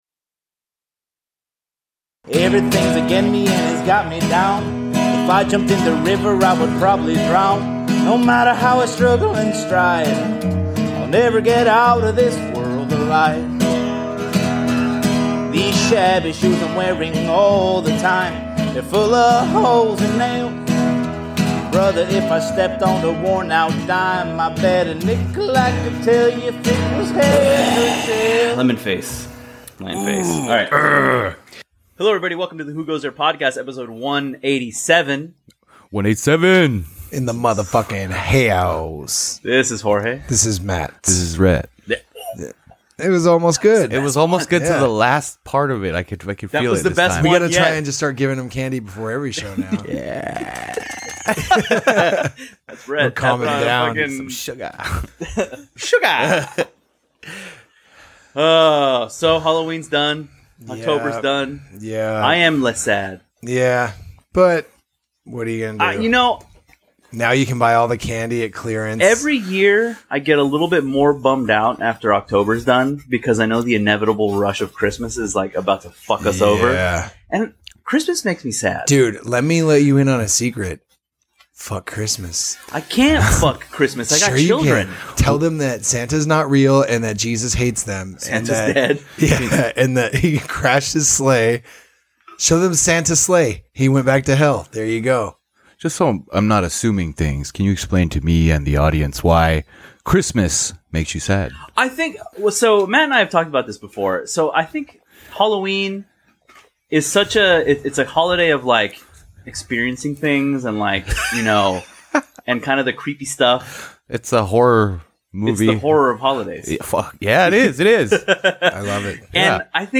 *UPDATE* The buzzing that was coming through in the episode has been fixed.